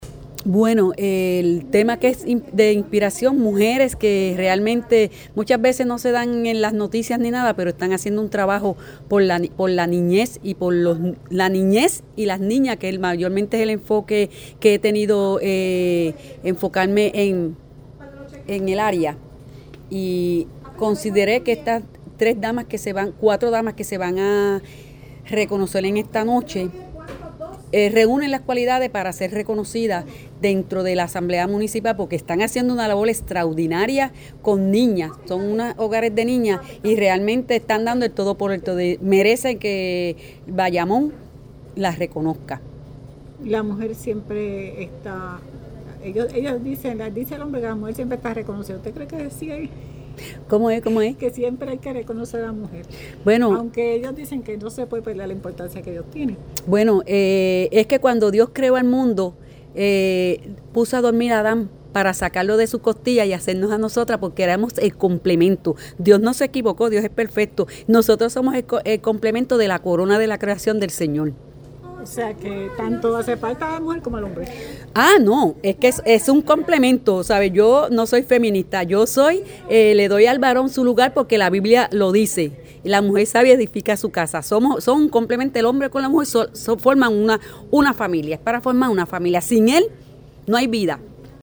En el marco de la Semana de la Mujer la Legislatura Municipal de Bayamón rindió homenaje a cuatro mujeres cuya trayectoria refleja compromiso, liderazgo y vocación de servicio - Foro Noticioso Puerto Rico
Por su parte Elba Pintado, Presidenta de la Comisión de la Mujer de la Legislatura Municipal de Bayamón y organizadora de la actividad, reconoció la aportación de las homenajeadas al prestigio de la ciudad.